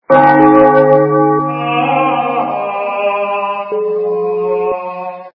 Церковна - Удар колокола і пения святого монаха Звук Звуки Церковна - Удар колоколу і спів святого монаха
При прослушивании Церковна - Удар колокола і пения святого монаха качество понижено и присутствуют гудки.